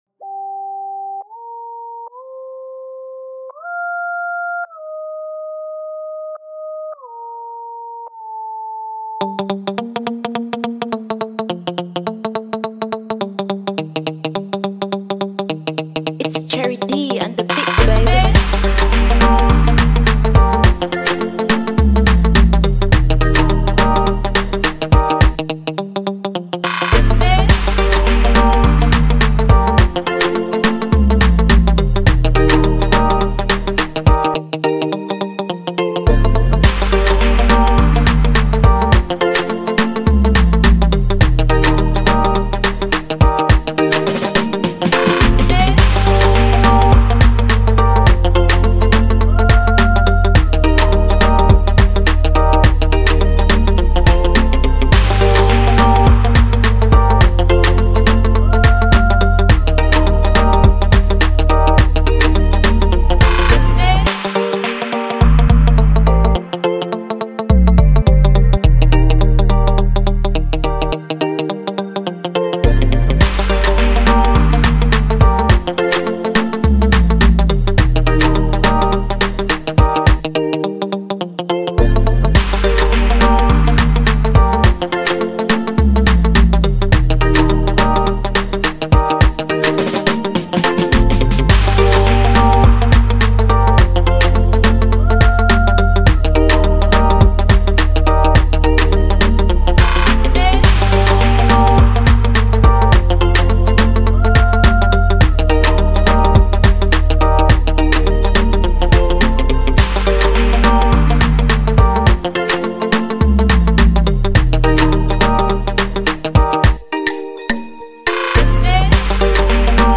2020-03-23 1 Instrumentals 0
Free beat instrumental